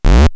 Abb. 24: Das Signal an der Lichtschranke, wenn die Leiter fällt. Die Aufzeichnung hat der akustische Eingang des Rechners, die Soundkarte, vorgenommen.
fallendeleiter.wav